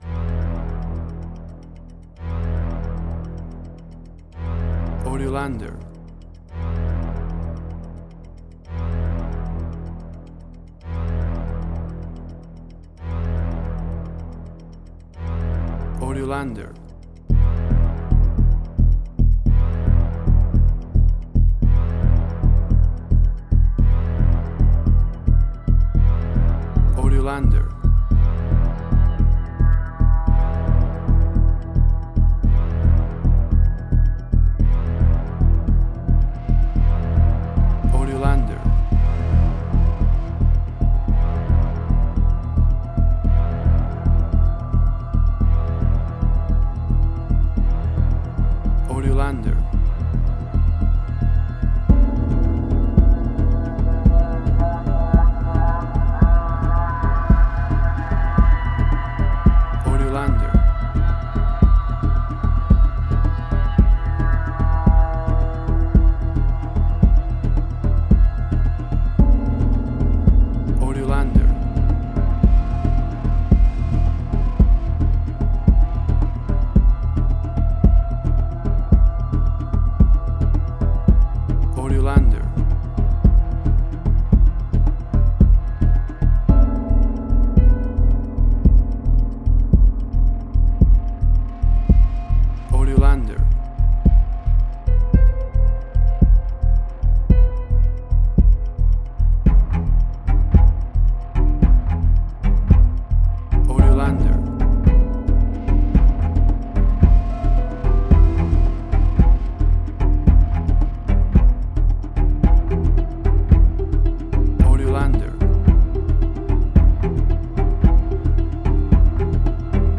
Modern Science Fiction Film
Tempo (BPM): 111